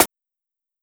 Snare (Ordinary Life).wav